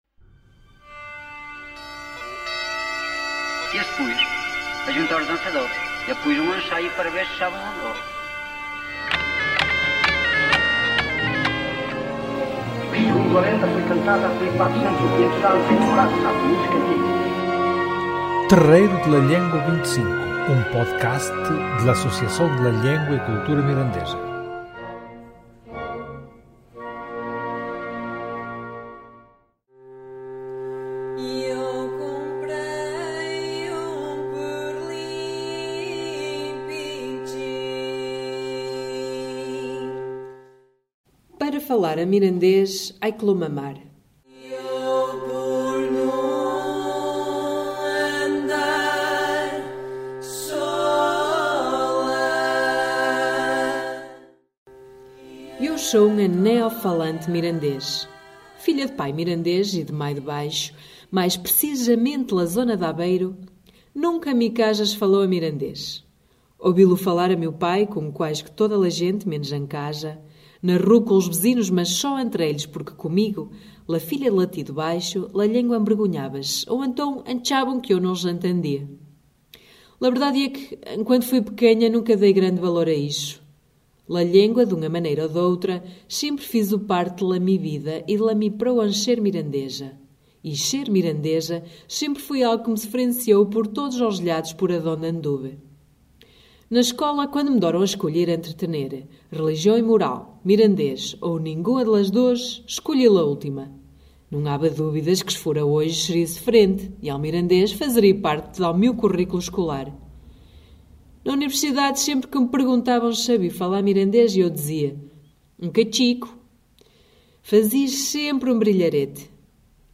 Música: Perlinpinchin, trad. mirandés, por Las Çarandas